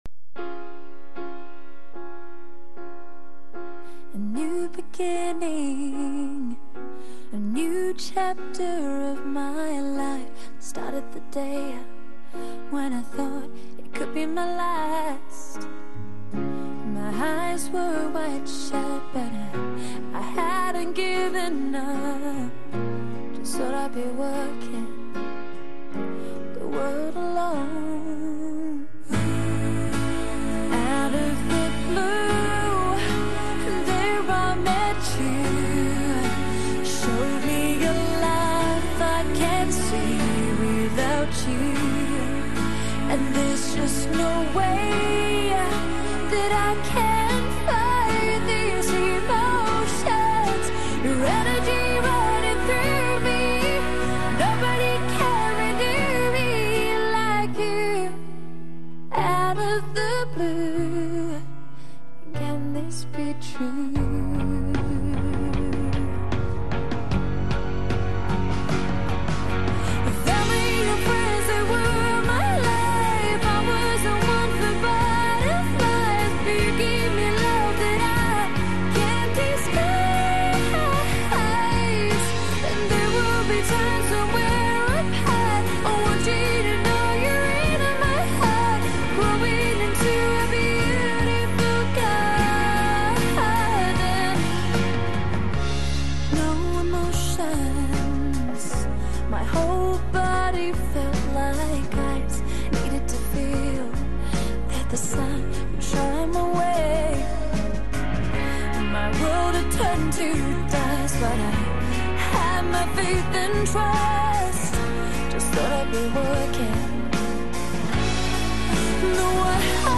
Pop Rock, Pop Jazz, Ballad